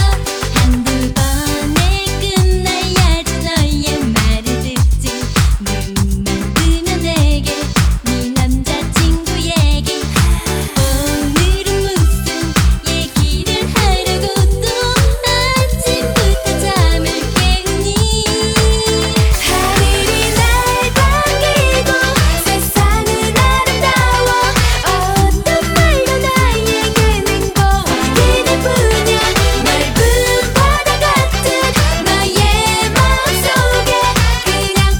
K-Pop
2000-08-16 Жанр: Поп музыка Длительность